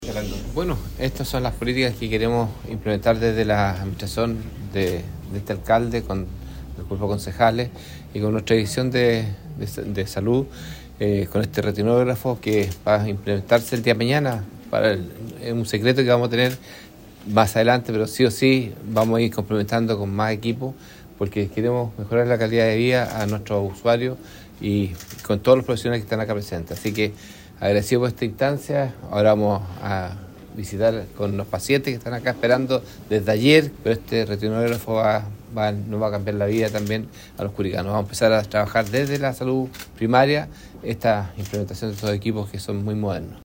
El alcalde de Curicó, George Bordachar, destacó la importancia de esta implementación, ya que actualmente, la UAPO de Curicó atiende en promedio 550 fondos de ojo mensuales y recibe más de 90 nuevos pacientes diabéticos cada mes, lo que aumenta la demanda por controles preventivos.
Alcalde-George-Bordachar.mp3